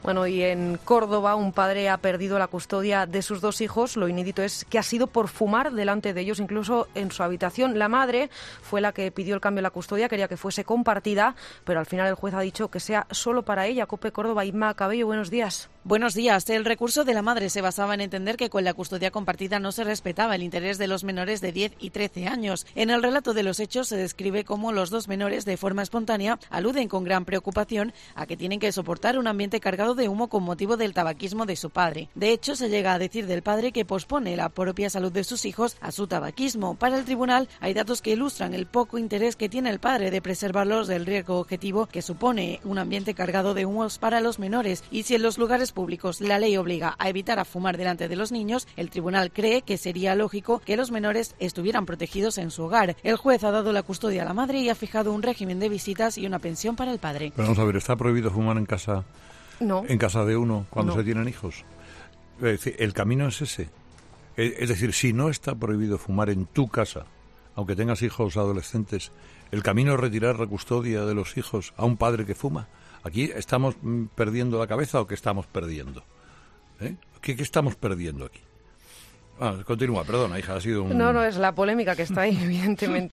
En su programa de este martes, Carlos Herrera ha reaccionado a esta noticia mostrando su incomprensión ante la decisión del juez.